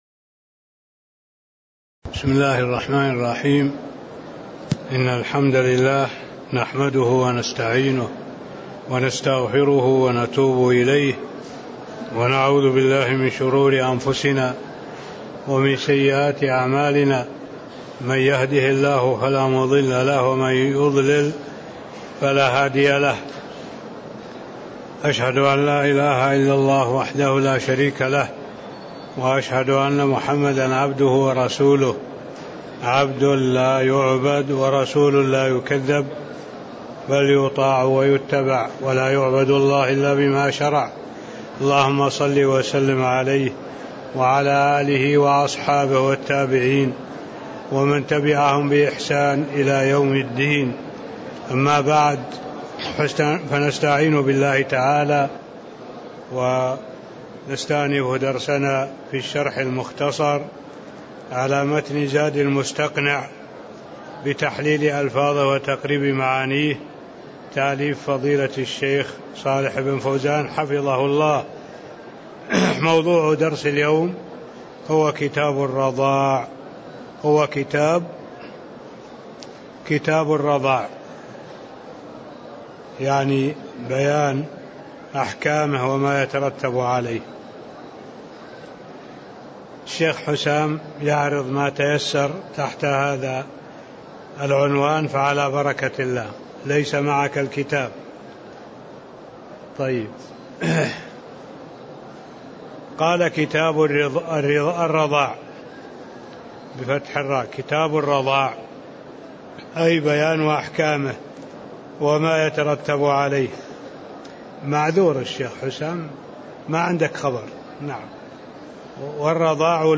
تاريخ النشر ١٨ شعبان ١٤٣٥ هـ المكان: المسجد النبوي الشيخ: معالي الشيخ الدكتور صالح بن عبد الله العبود معالي الشيخ الدكتور صالح بن عبد الله العبود قوله: والرضاع لغة مصّ الثدي (01)